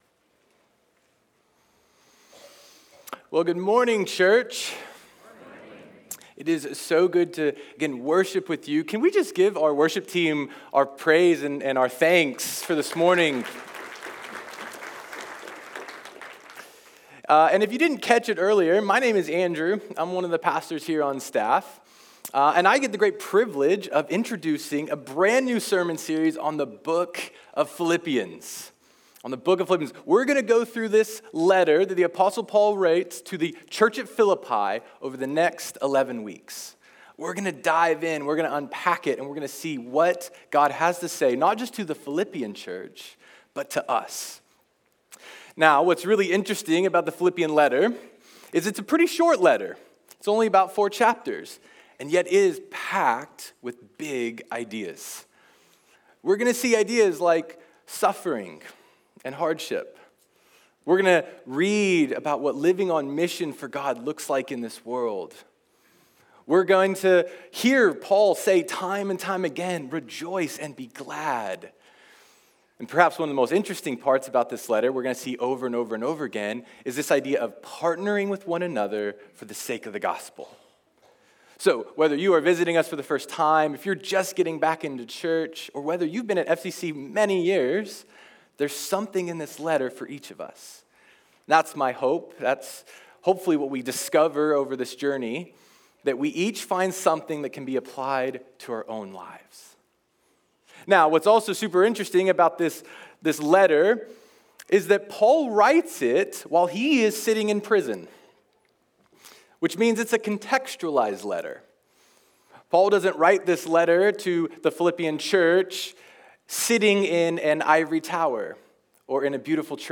Preaching and teaching audio from Faith Community Church